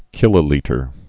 (kĭlə-lētər)